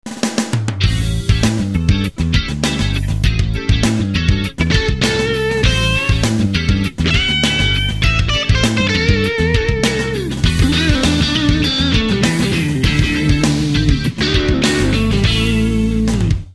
GT2 TWEED SOUND SAMPLE
Tech 21 SansAmp GT2 preamp
Yamaha Pacifica 120 and 112 guitars*
gt2tweed.mp3